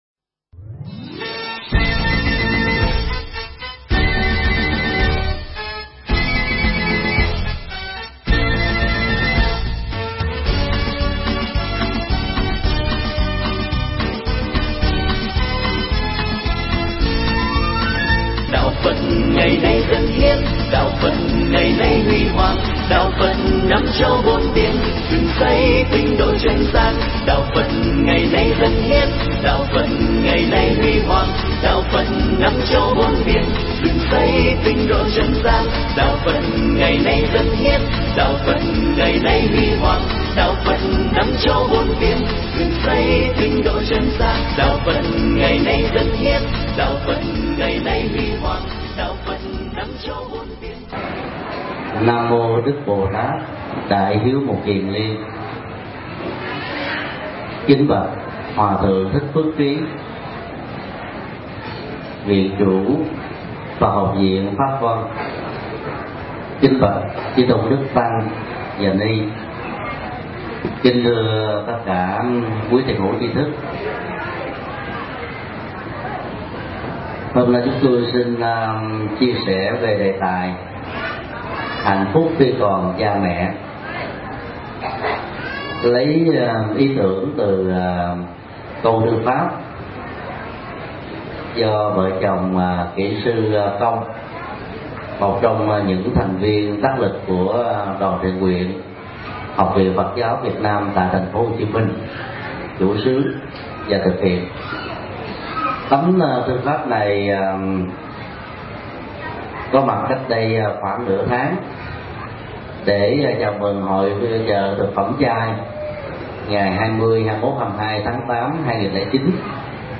Tải mp3 Pháp thoại Hạnh Phúc Khi Còn Mẹ Cha do thầy Thích Nhật Từ giảng Nhân Lễ Vu Lan Chùa Pháp Vân, ngày 03 ngày 09 năm 2009